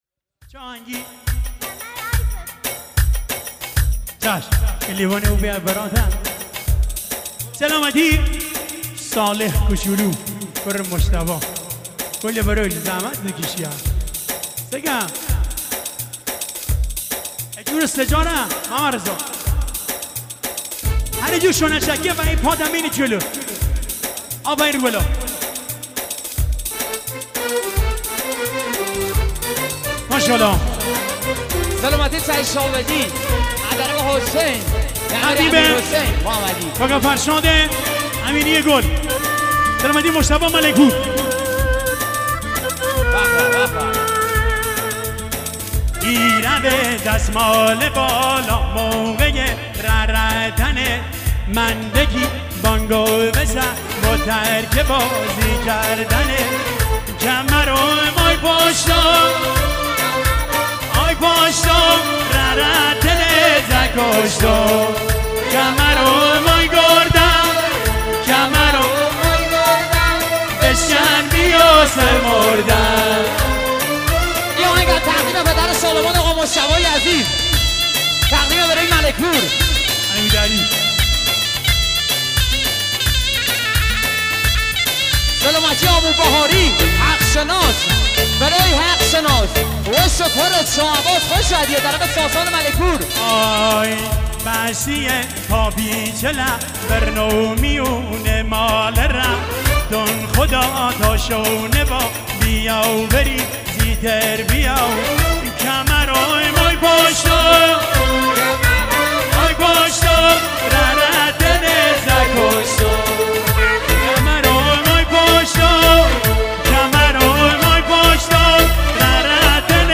بختیاری